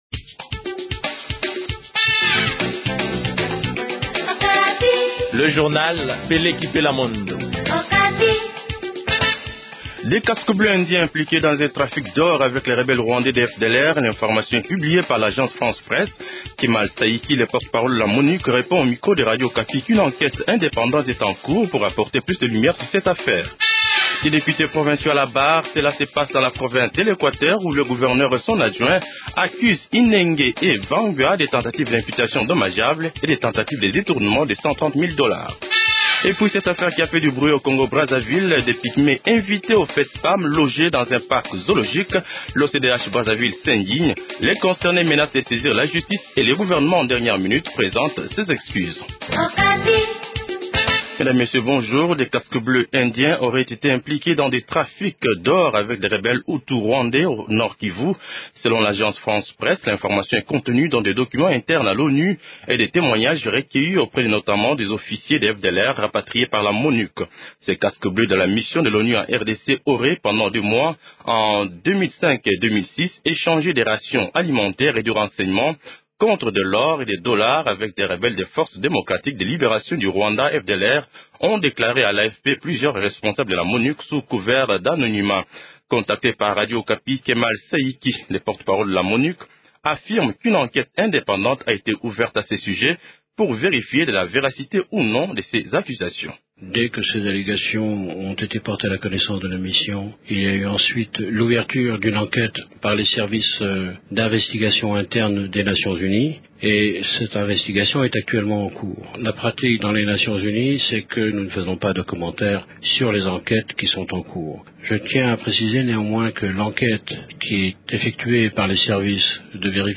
Journal Français Matin 08h00